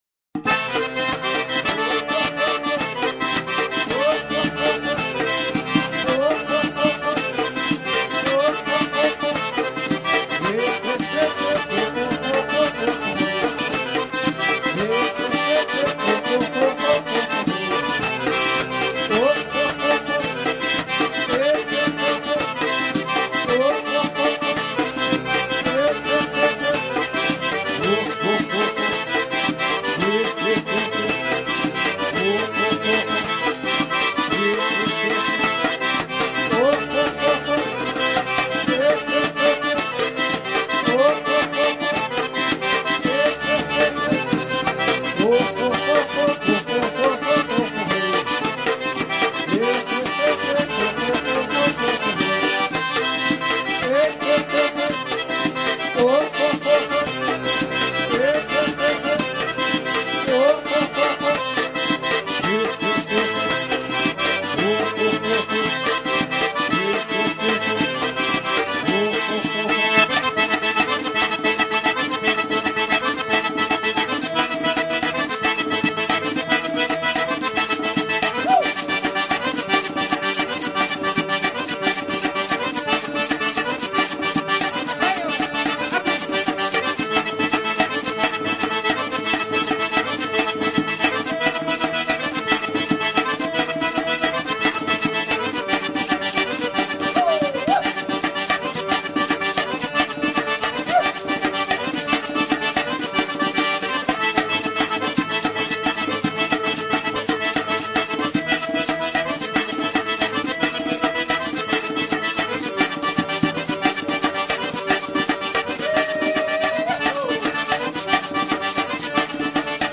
Mahalli Müziler